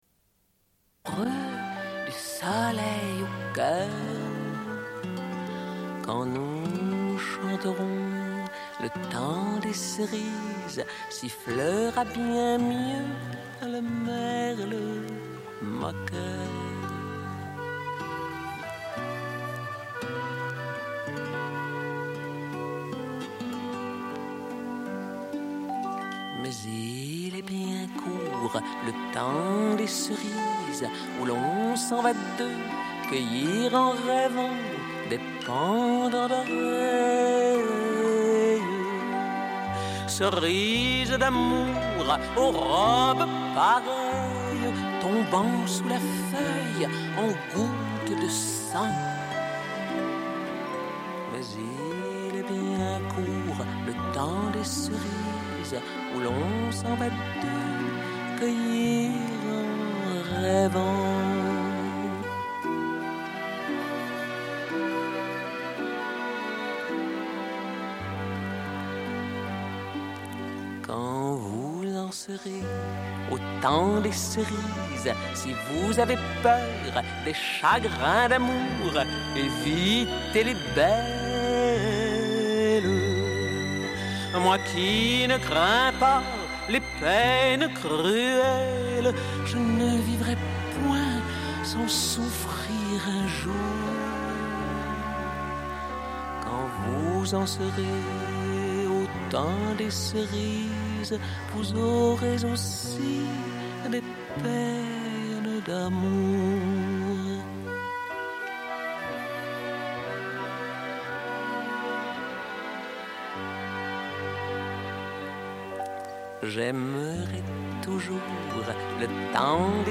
Une cassette audio, face B29:44